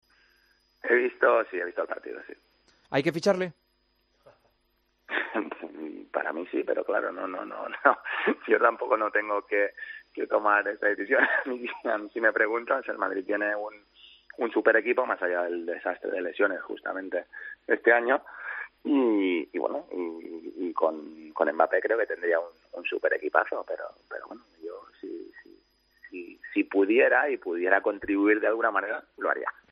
El tenista habló en El Partidazo de COPE sobre la posibilidad de que llegue la estrella del PSG al conjunto blanco, después del partido contra la Real Sociedad en Champions.